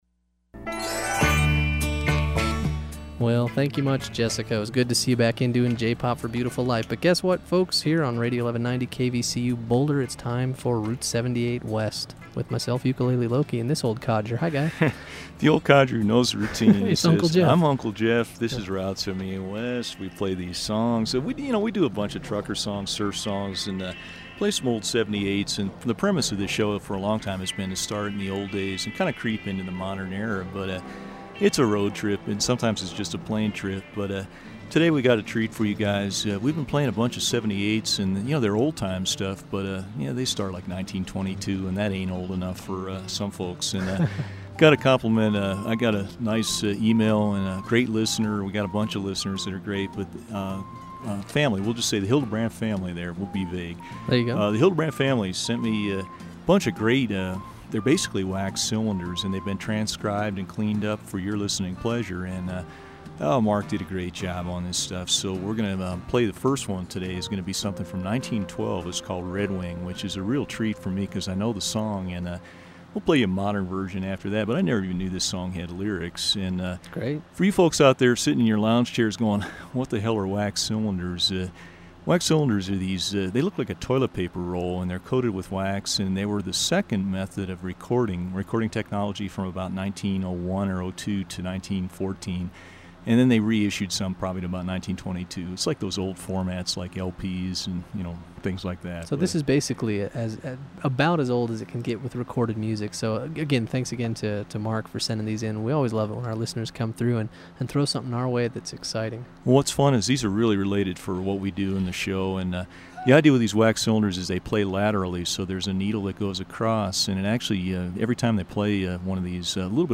Route 78 West, the best Alt-Country Music Show on the dial.